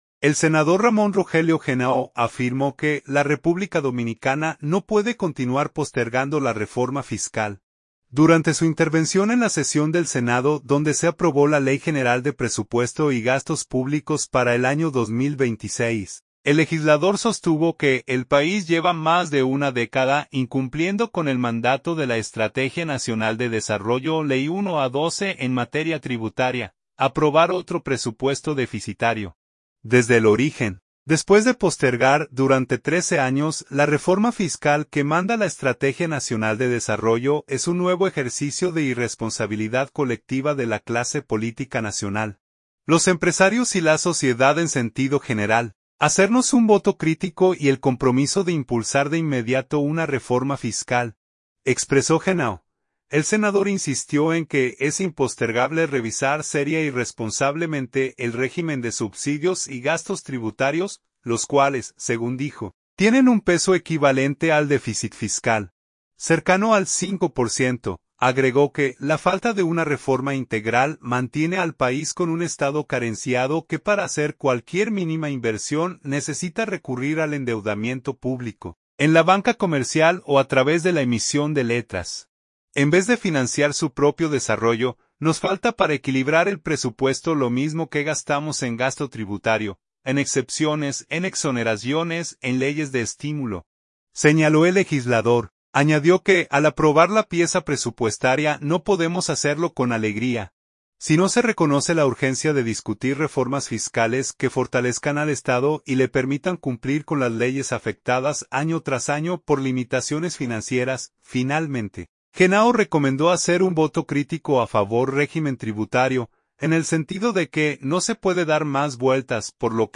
El senador Ramón Rogelio Genao afirmó que la República Dominicana no puede continuar postergando la Reforma Fiscal, durante su intervención en la sesión del Senado donde se aprobó la Ley General de Presupuesto y Gastos Públicos para el año 2026.